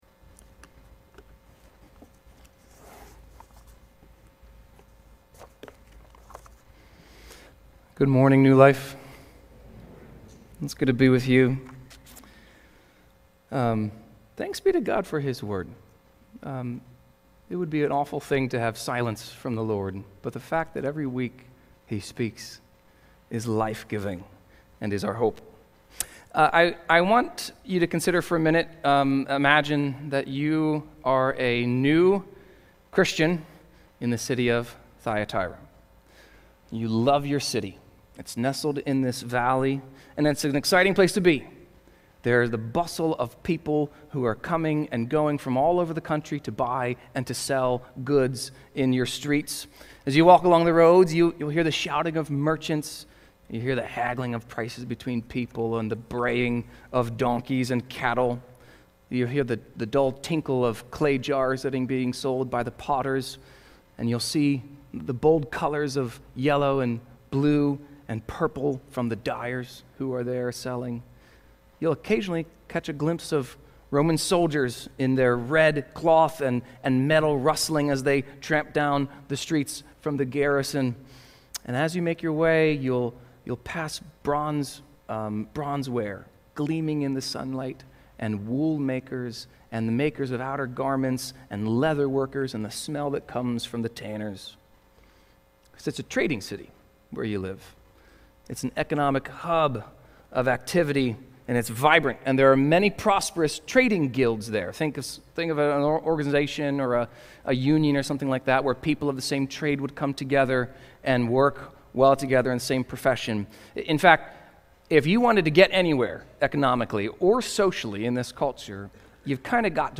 Sermon-4.4.25.mp3